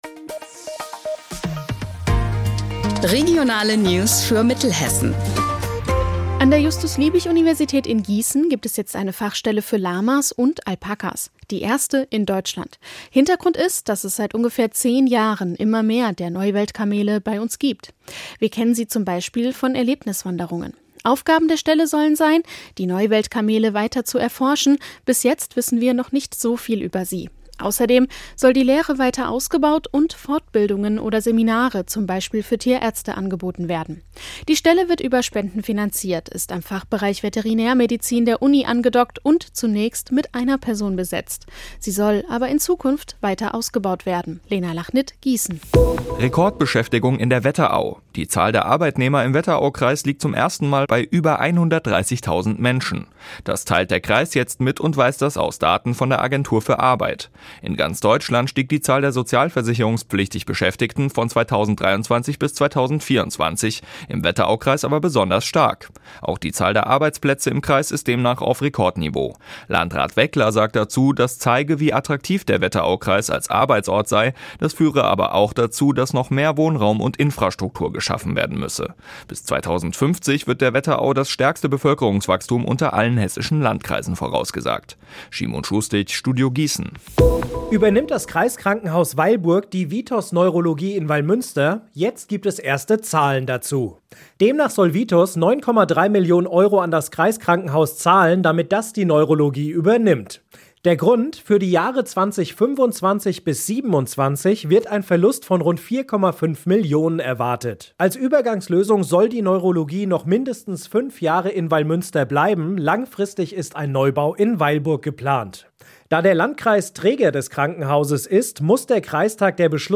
Mittags eine aktuelle Reportage des Studios Gießen für die Region.